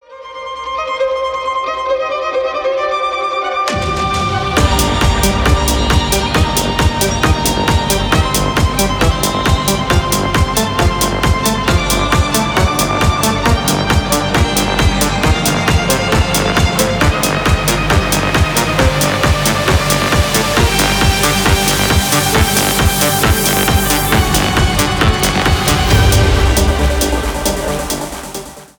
Танцевальные
громкие # клубные